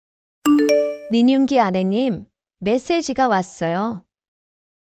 Genre: Nada dering Korea